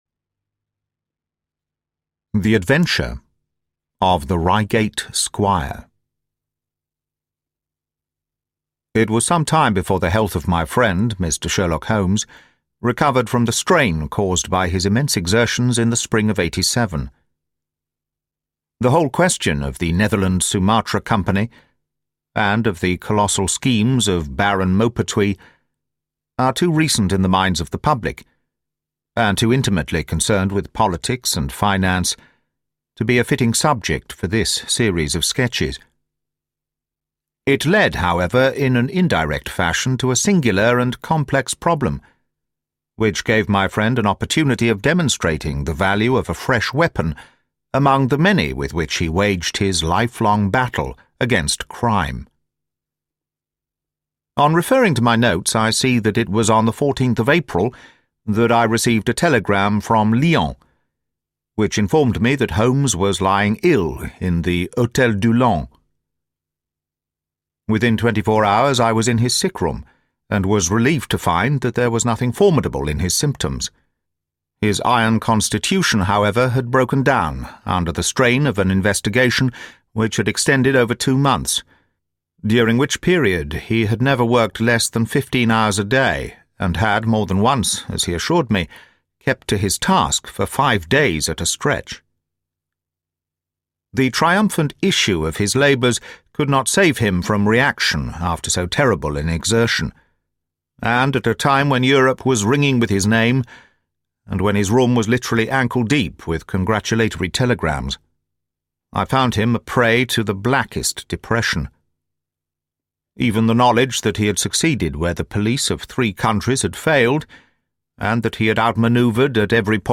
The Priory School: Mystery and Danger Unfold (Audiobook)